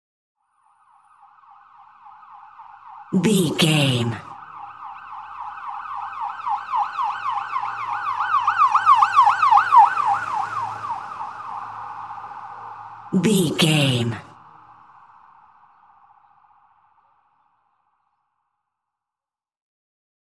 Ambulance Ext Passby Short Siren 2
Sound Effects
urban
chaotic
emergency